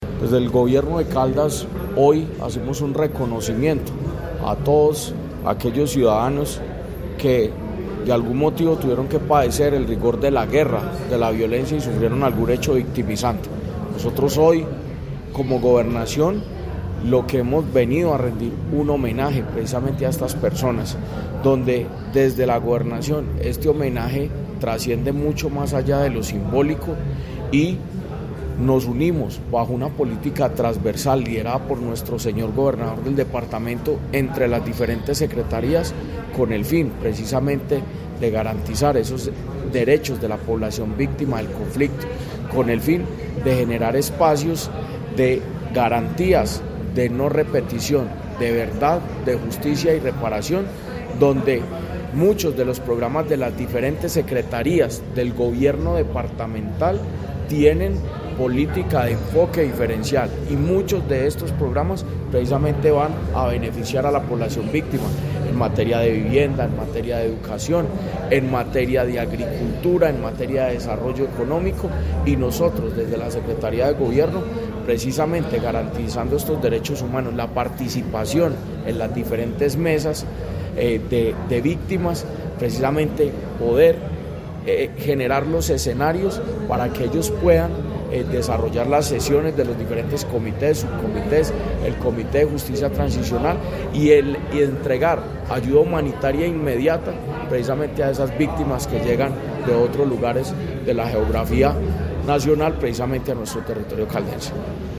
art16318-jorge-andres-gomez-escudero-secretario-de-gobierno-de-caldas-conmemoracion-victimas.mp3